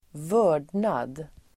Uttal: [²v'ö:r_dnad]